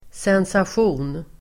Uttal: [sensasj'o:n]